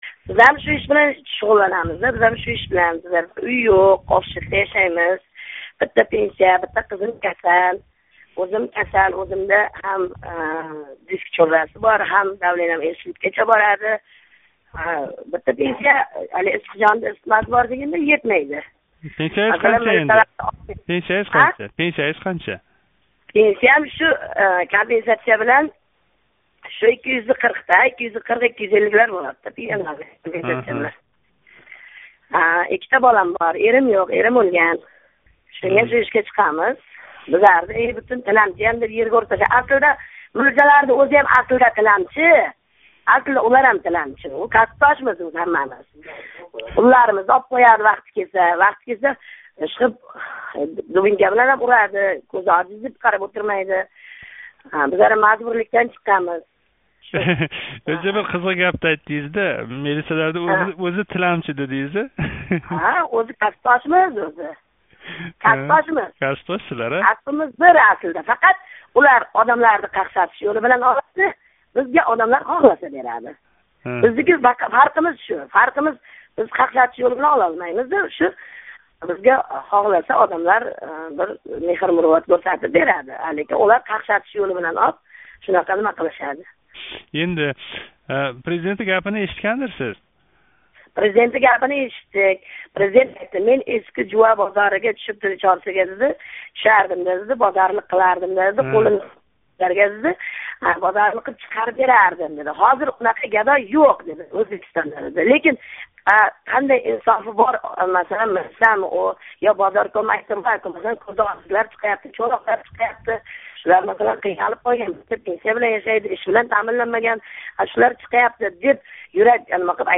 Киритиш (Embed) Улашиш Самарқандлик ногирон тиланчи аёл билан суҳбат: билан Озодлик радиоси Киритиш (Embed) Улашиш Коддан алмашув буферингизга нусха кўчирилди.